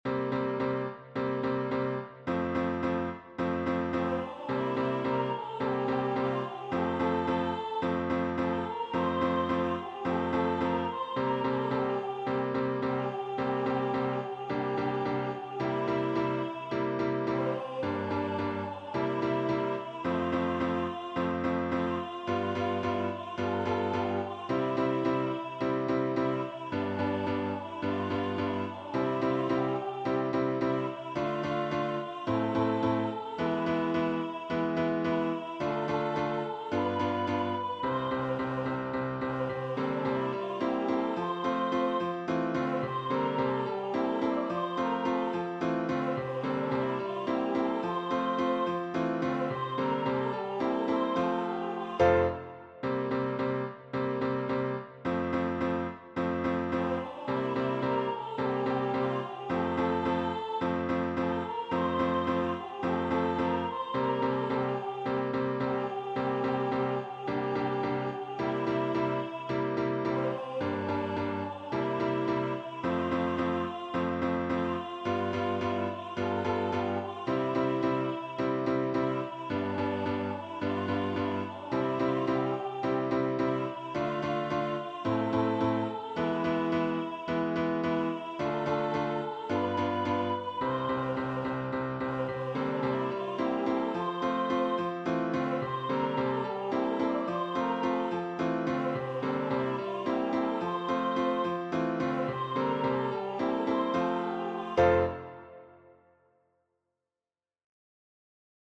rehearsal file